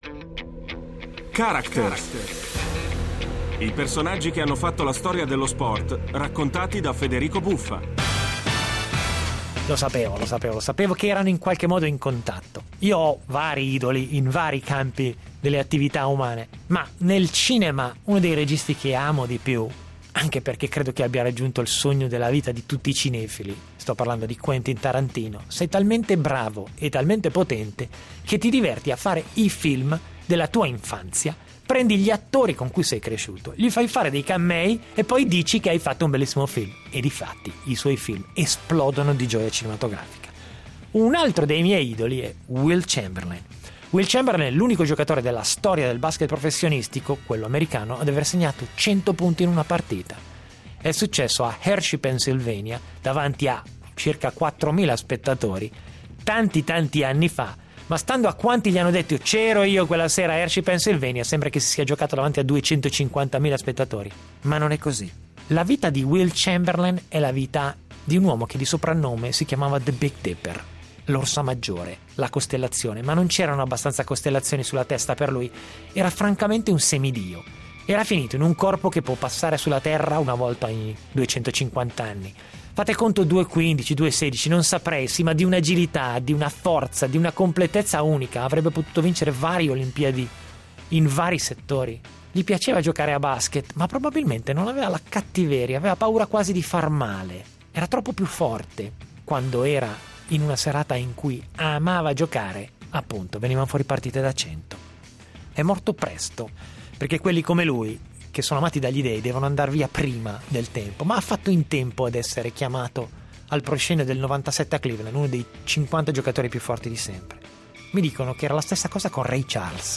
L'incredibile vita di Wilton "Wilt" Norman Chamberlain, probabilmente il cestita NBA piiù dominante nella storia della pallacanestro, raccontata da Federico Buffa.